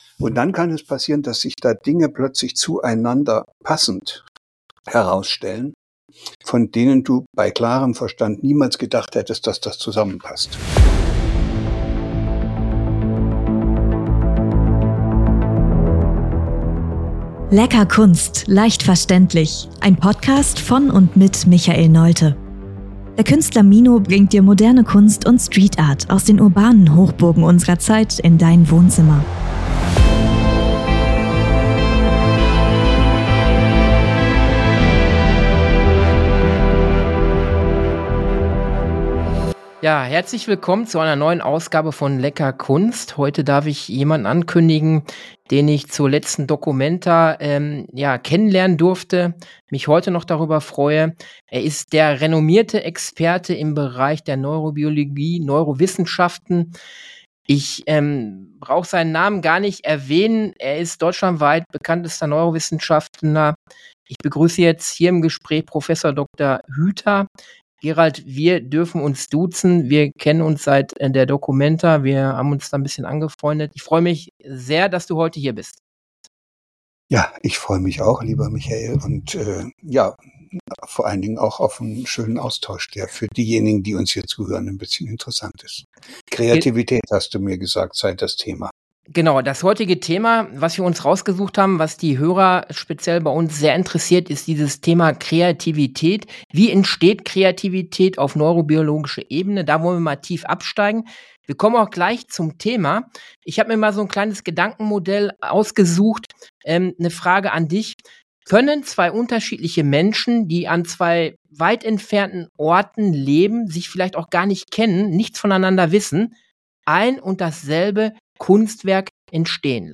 Interview mit Prof. Dr. Gerald Hüther